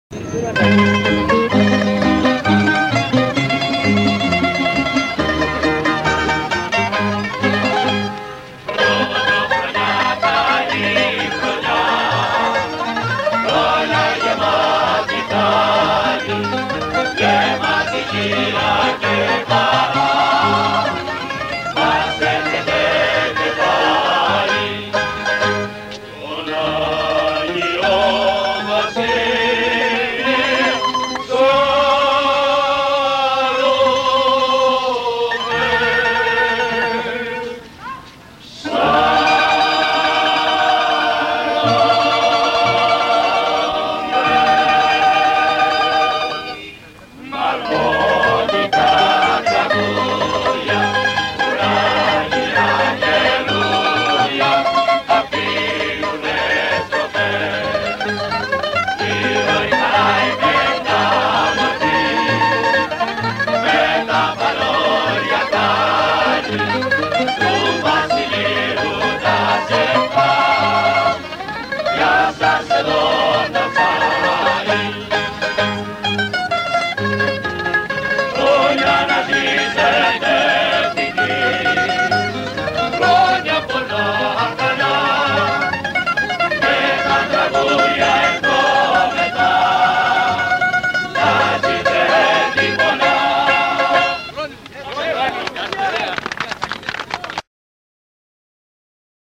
Zακυνθινά κάλαντα